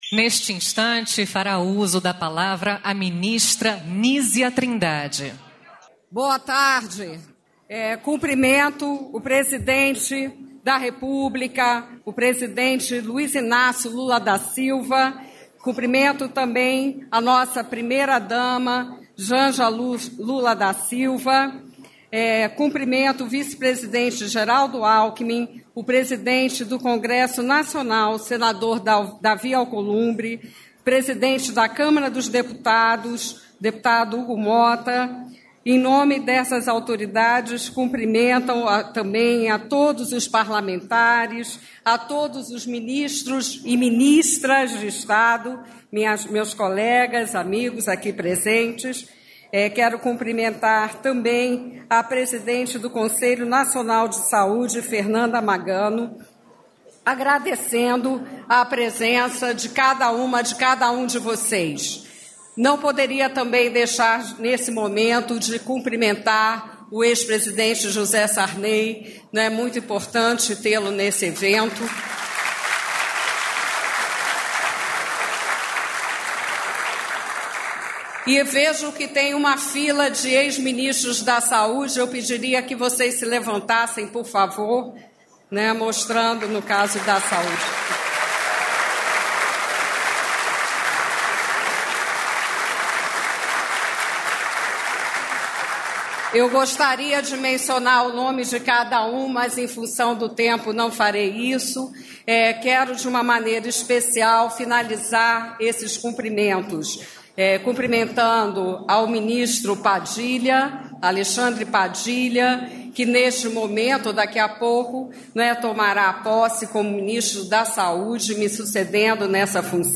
Novo campus do Instituto Tecnológico da Aeronáutica será implantado na Base Aérea de Fortaleza, com investimento total de R$ 180 milhões para as obras. Ouça a fala dos ministros José Múcio, da Defesa, e Camilo Santana, do MEC, nesta quinta-feira (19)